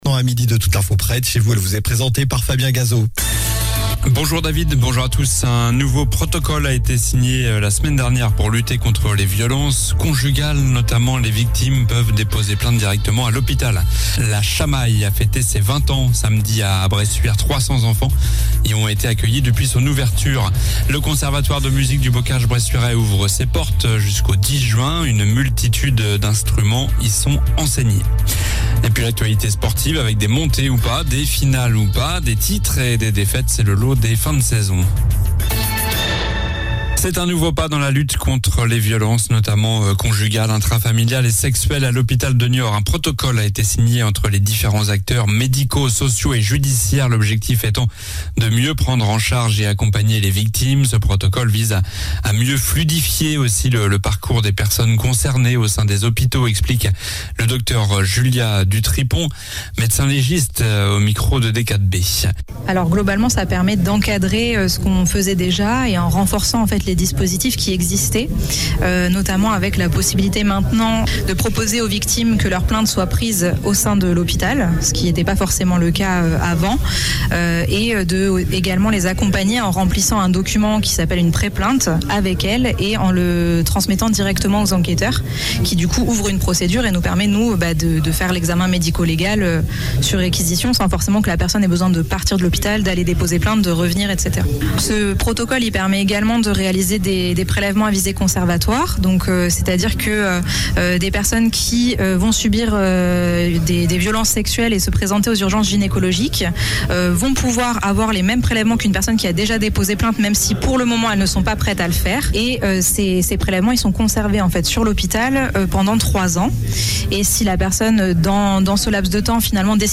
Journal du lundi 05 juin (midi)